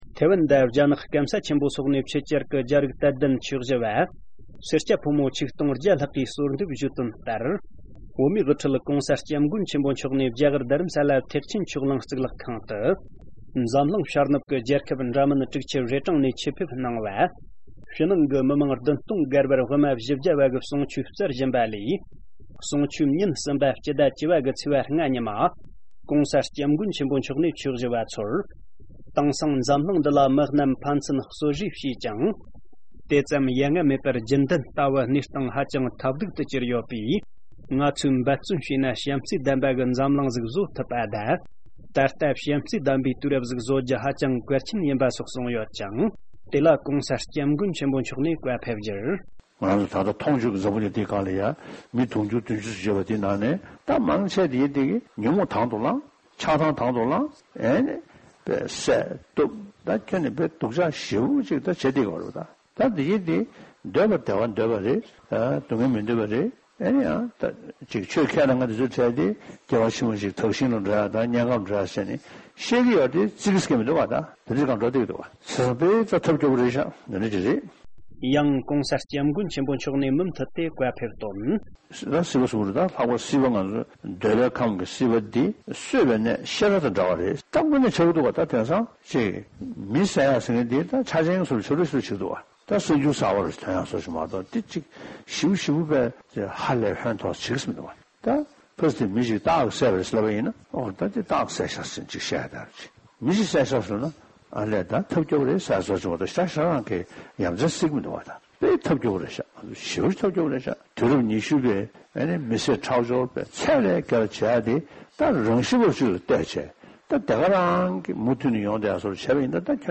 ༸གོང་ས་མཆོག་ནས་བྱམས་བརྩེ་ལྡན་པའི་དུས་རབས་ཤིག་བཟོ་གལ་ཆེ་བའི་སྐོར་ལམ་སྟོན་བཀའ་སློབ་གནང་འདུག